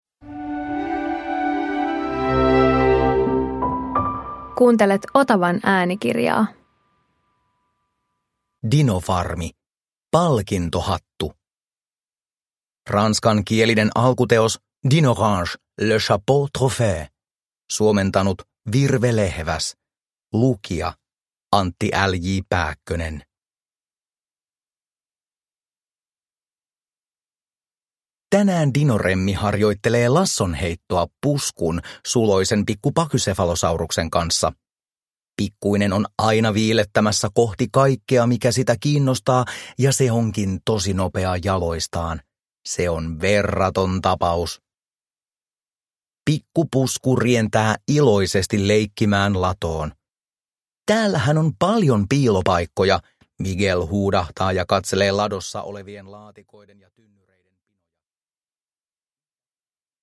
Dinofarmi - Palkintohattu – Ljudbok – Laddas ner